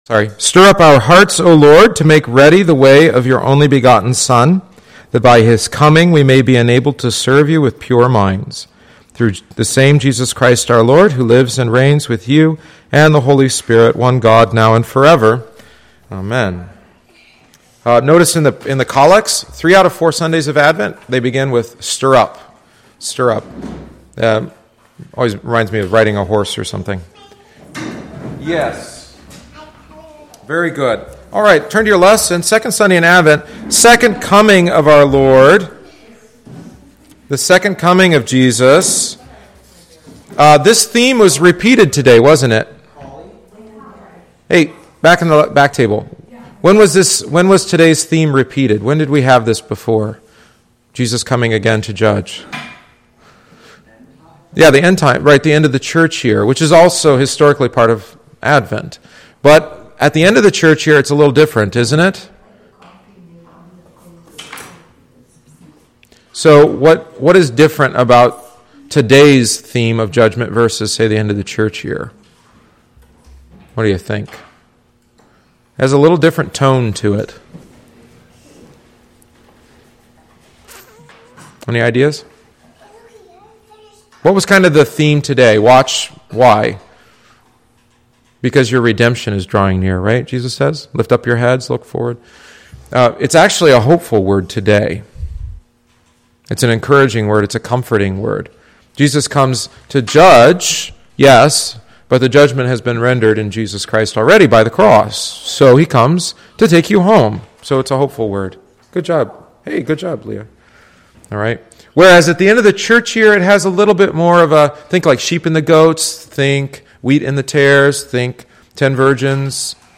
This week we began a video-based study of Lutheran hymnody and liturgy.Â The attached audio omits the videoÂ and includes only our discussion.